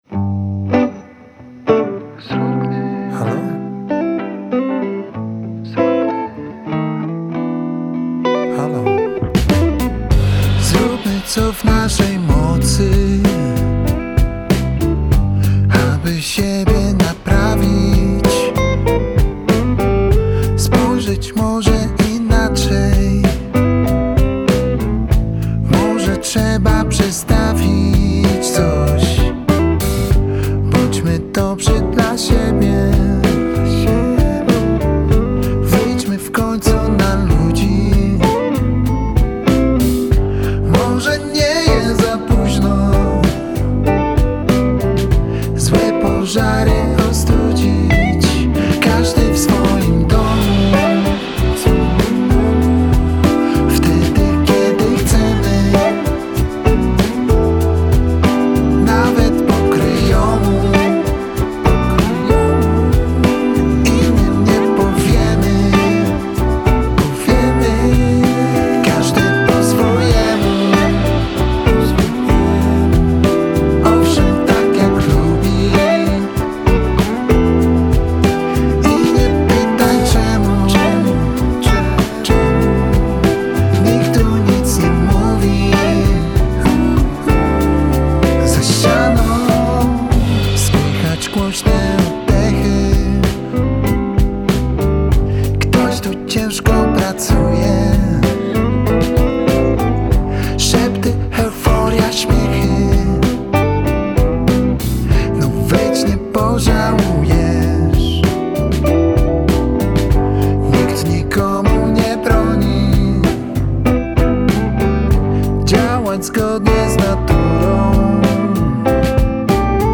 klasyczny, rockowo-popowy utwór